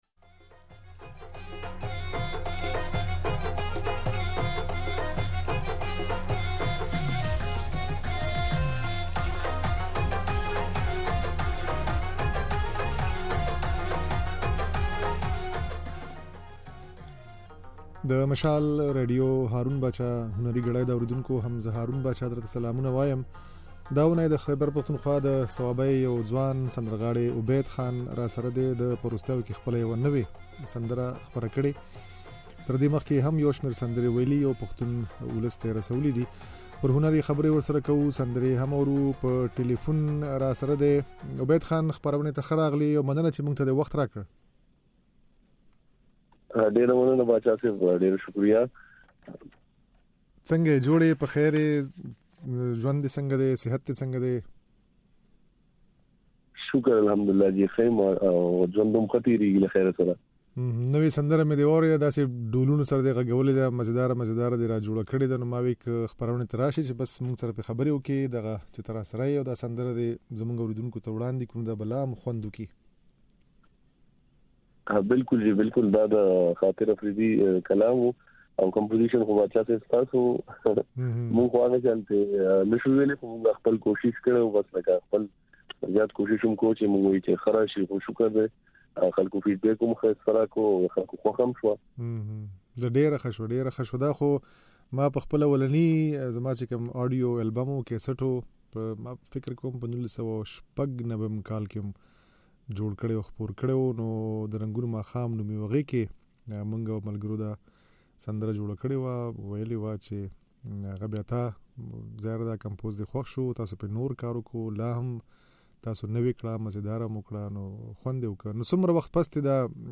يو ځوان سندرغاړی